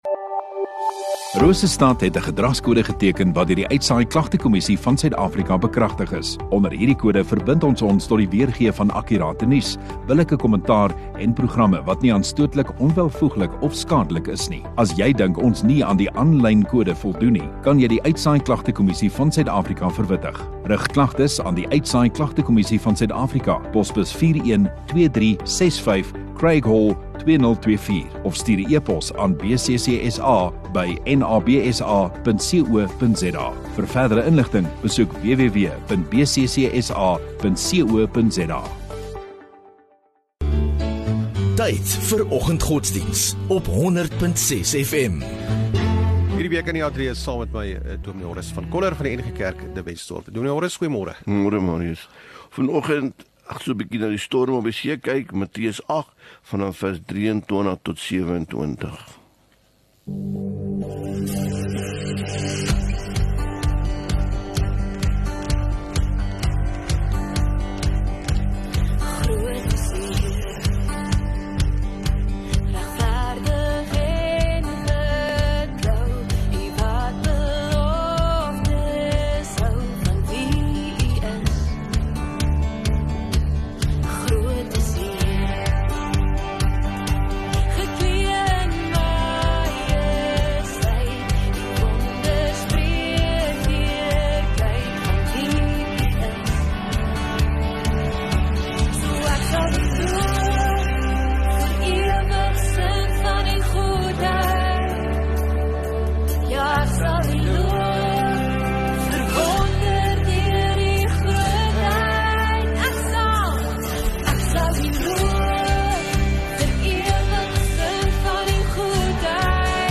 22 Aug Donderdag Oggenddiens